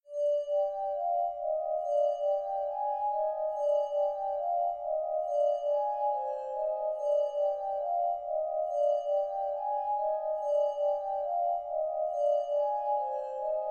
陷阱式合成器循环
Tag: 140 bpm Trap Loops Synth Loops 2.31 MB wav Key : D